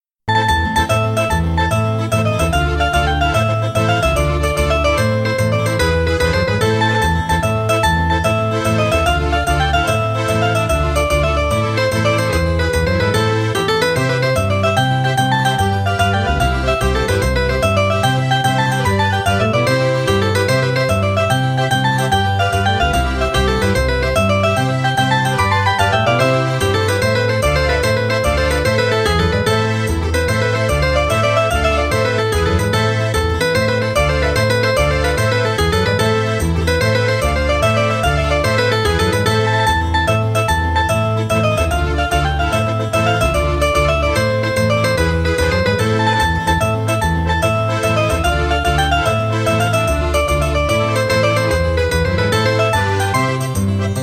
• Качество: 183, Stereo
красивые
веселые
без слов
инструментальные
бодрые
Melodic
Приятная, живая и весёлая мелодия